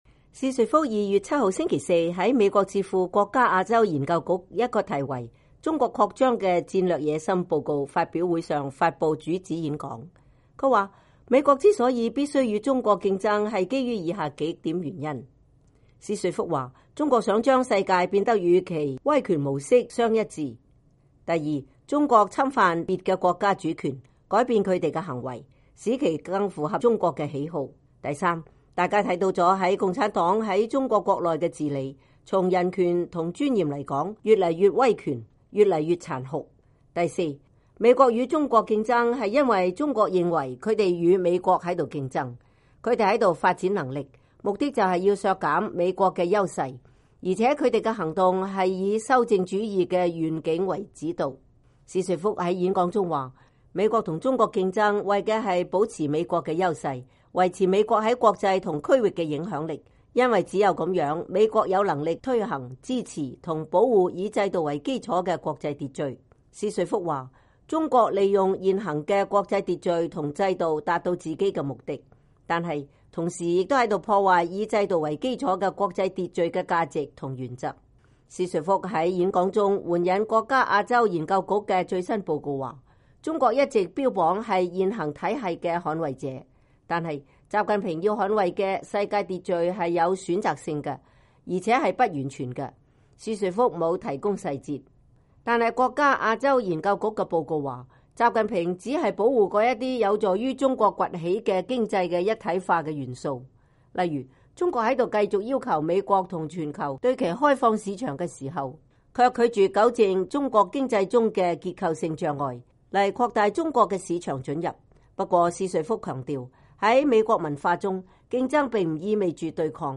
薛瑞福星期四在美國智庫“國家亞洲研究局”（The National Bureau of Asian Research）一個題為“中國擴張的戰略野心”報告發布會上發布主旨演講。